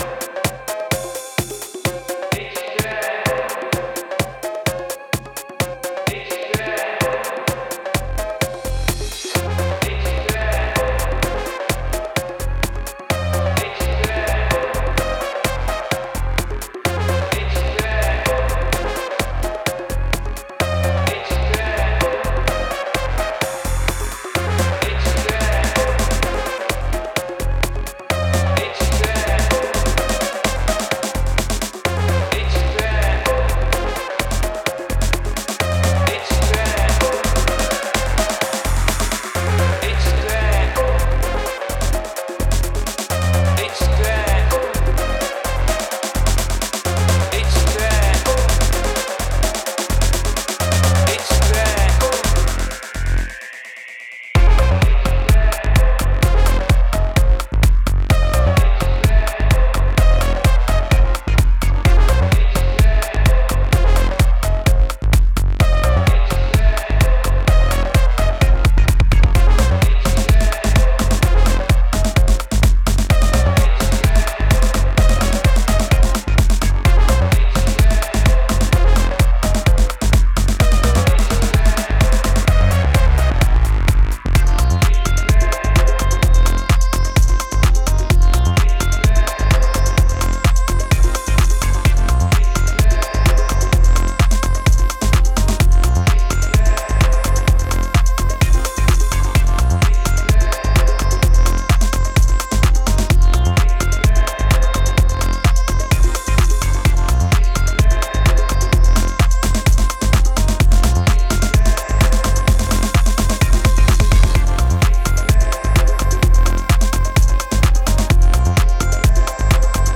Built for late-night floors and intimate settings alike
timeless grooves